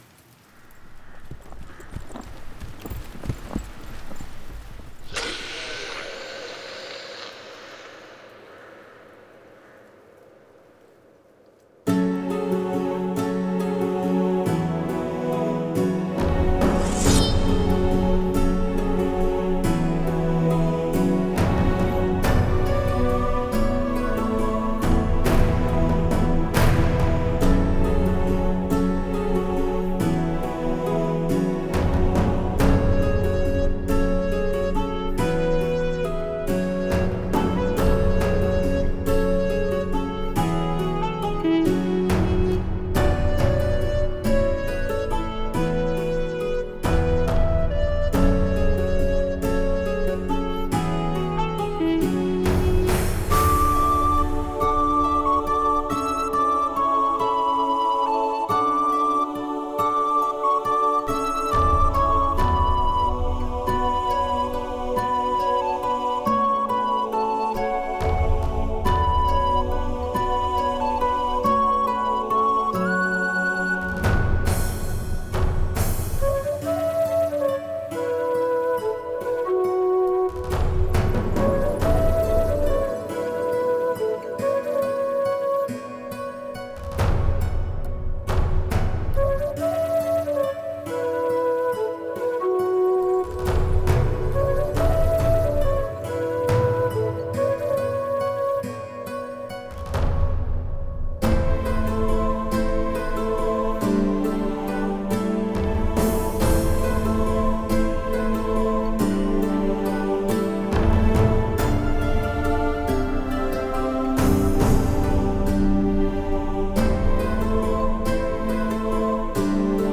New age Нью эйдж Музыка релакс Relax Музыка нью эйдж